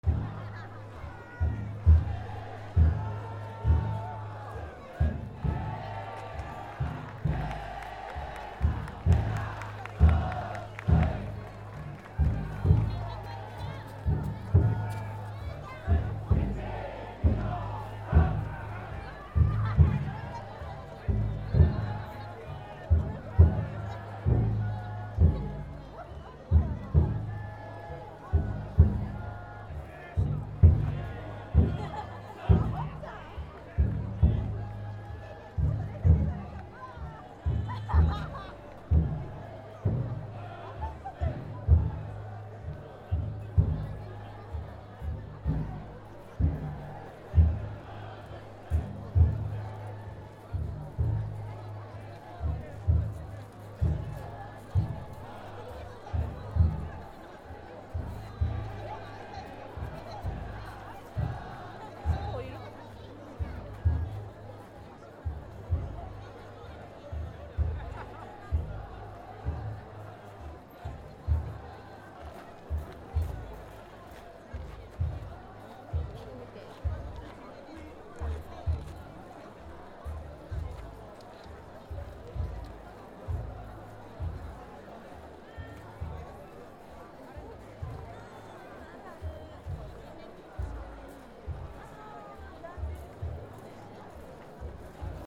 / C｜環境音(人工) / C-55 ｜祭り
祭り 032境内担ぎ